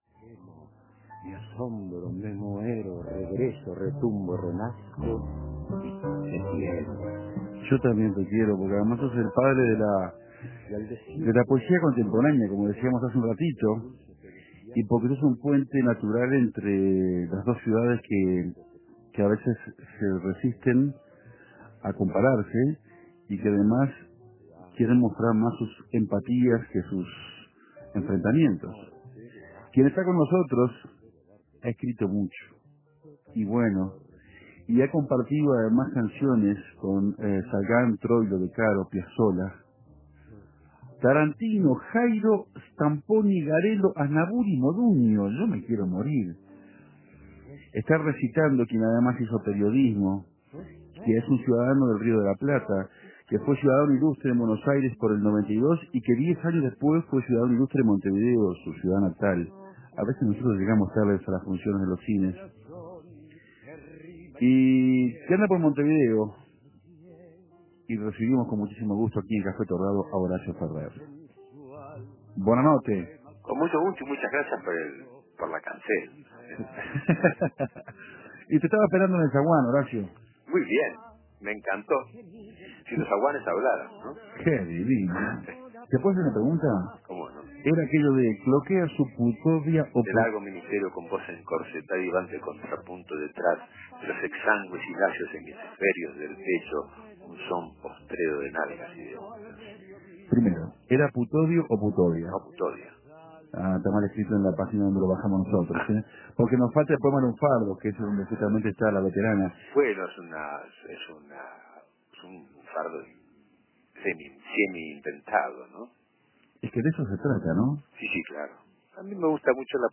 Chamullamos lunfardías milongueras junto a Horacio Ferrer mediante un contacto telefónico en Argentina.